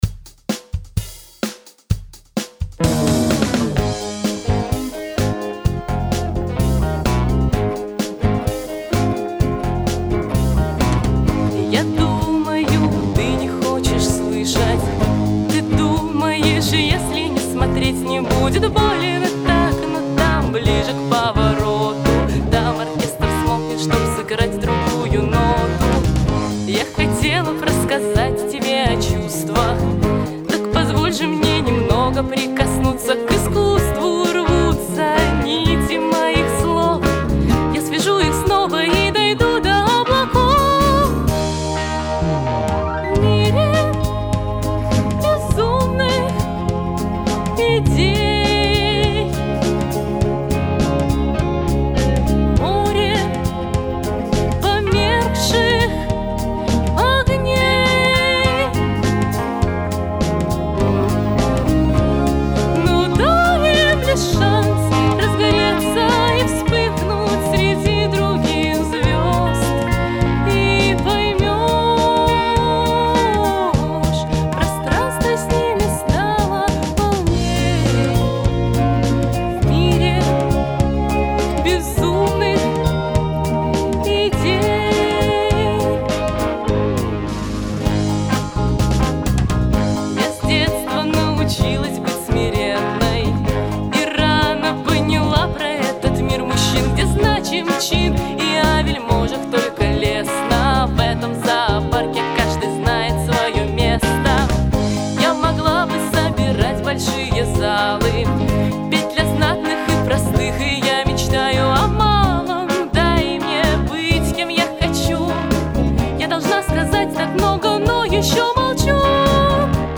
Записано в студии